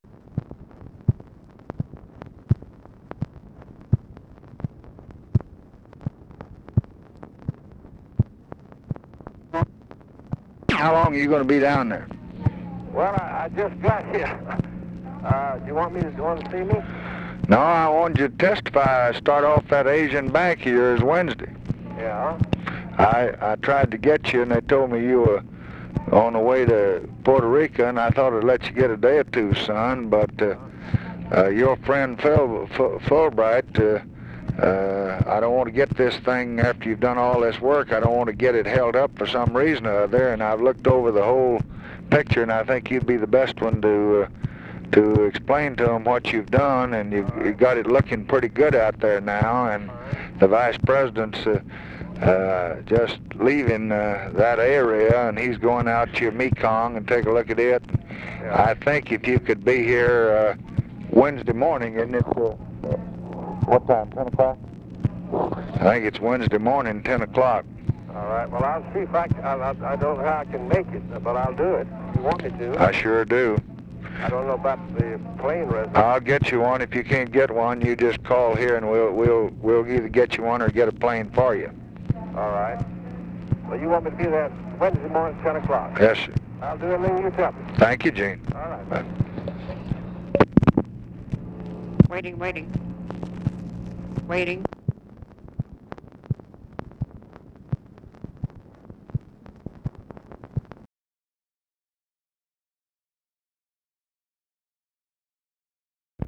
Conversation with EUGENE BLACK, February 12, 1966
Secret White House Tapes